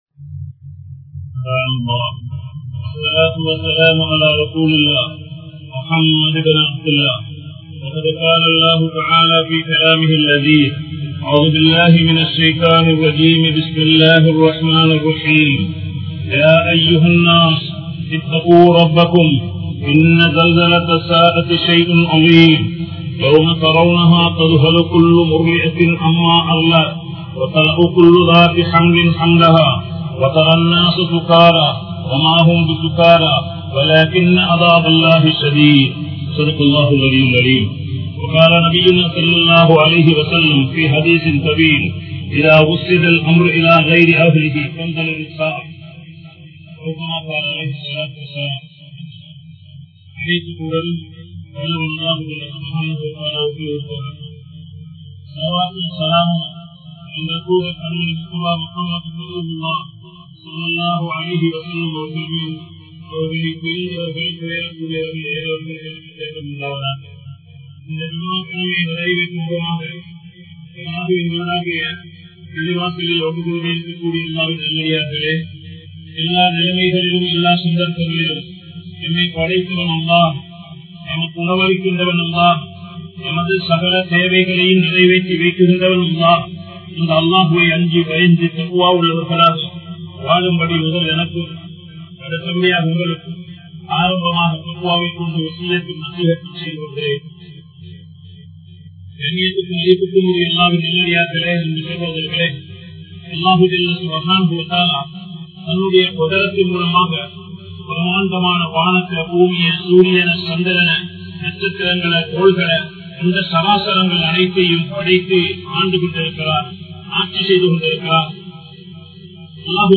Qiyaamath Naalin Adaiyaalangal (கியாமத் நாளின் அடையாளங்கள்) | Audio Bayans | All Ceylon Muslim Youth Community | Addalaichenai
Kollupitty Jumua Masjith